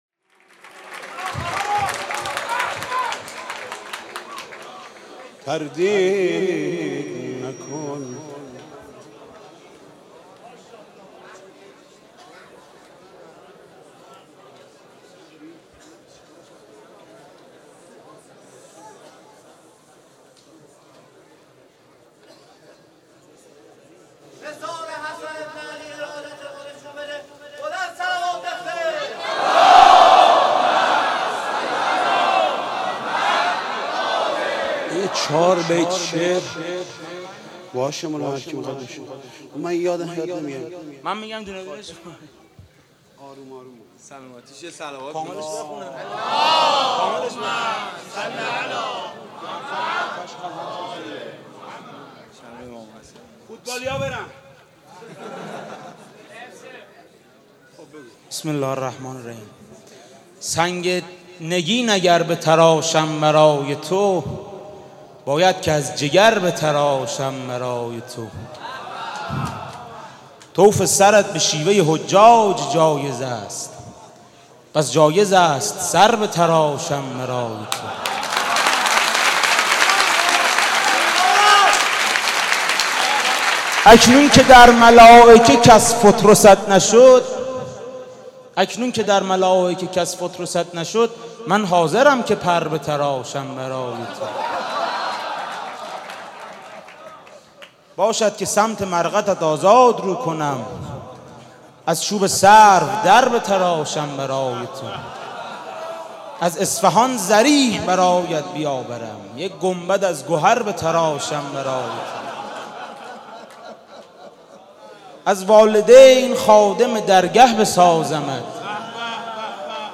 شعرخوانی م مناجات